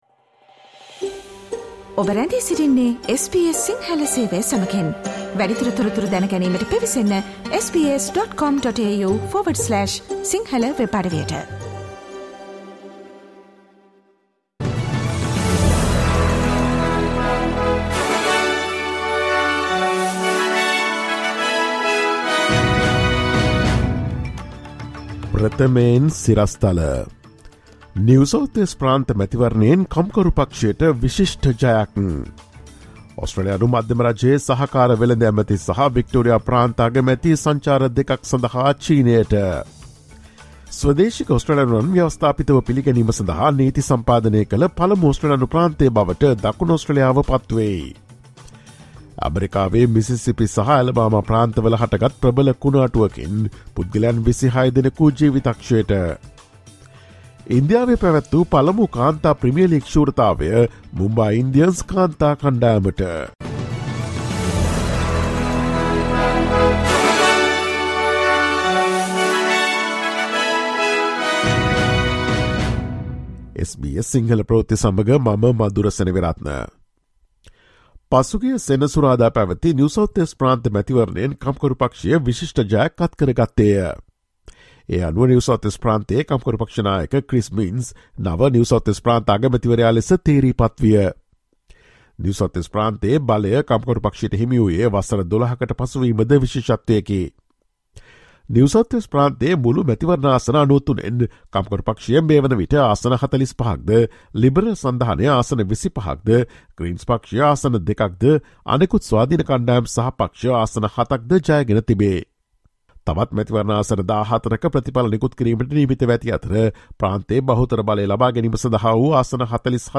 ඔස්ට්‍රේලියාවේ නවතම පුවත් මෙන්ම විදෙස් පුවත් සහ ක්‍රීඩා පුවත් රැගත් SBS සිංහල සේවයේ 2023 මාර්තු 27 වන දා සඳුදා වැඩසටහනේ ප්‍රවෘත්ති ප්‍රකාශයට සවන් දෙන්න.